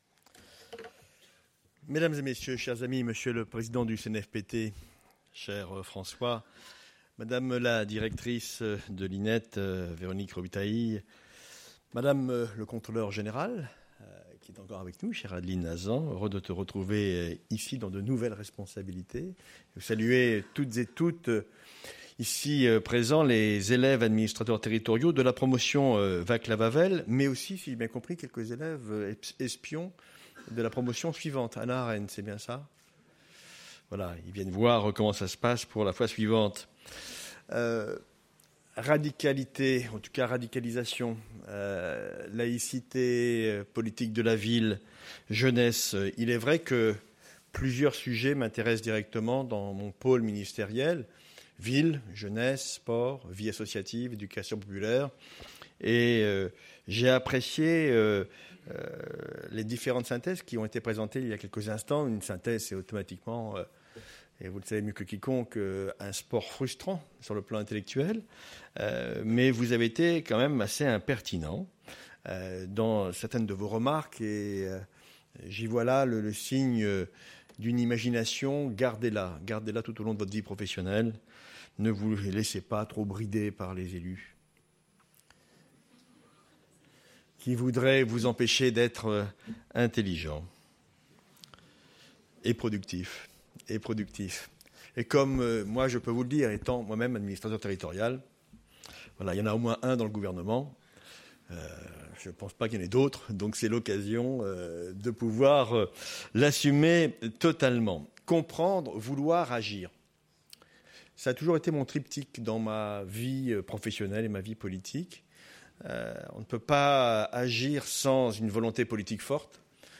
Écoutez l’intervention de Patrick Kanner, ministre de la Ville, de la Jeunesse et des Sports